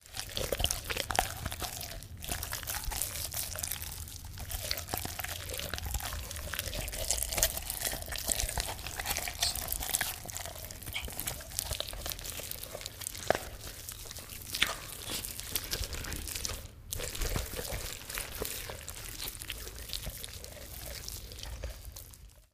Intestine | Sneak On The Lot
Horror flesh ripping and digging in internal organs